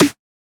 Index of /99Sounds Music Loops/Drum Oneshots/Twilight - Dance Drum Kit/Snares